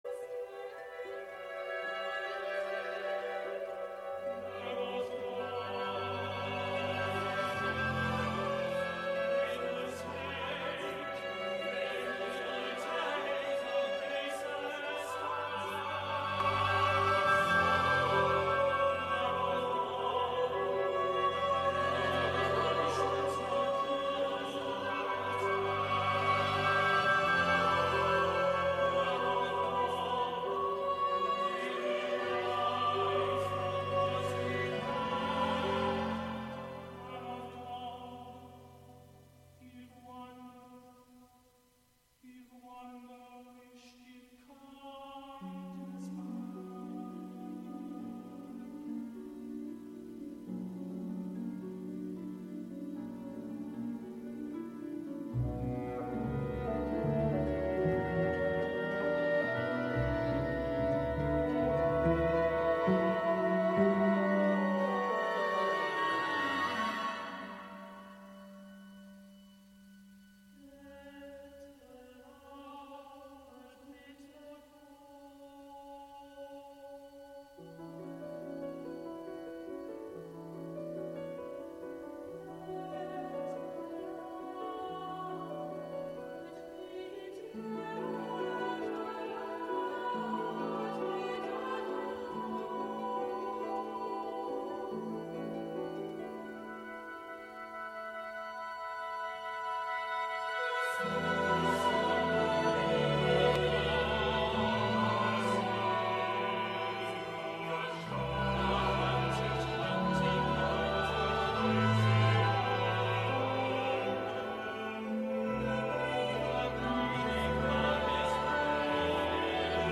perform live from New York City